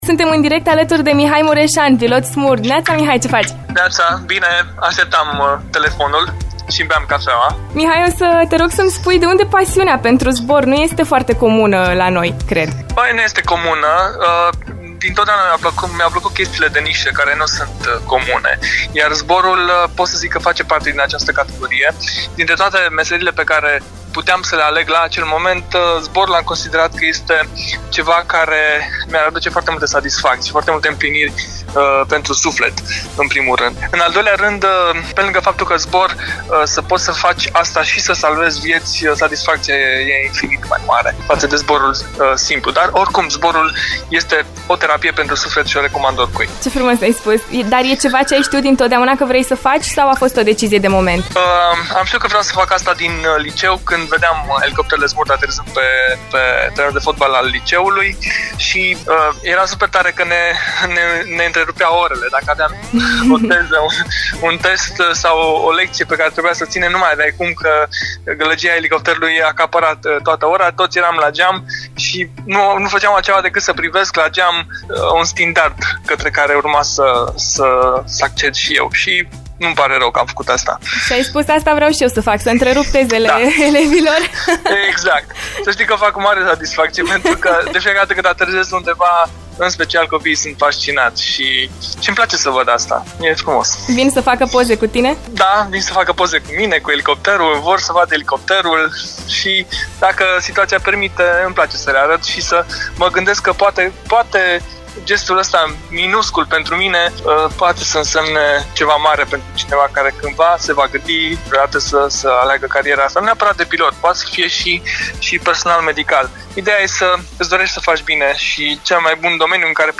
Cum a decis că acesta e drumul pe care trebuie să-l urmeze și ce simte atunci când este în zbor îți spune chiar el: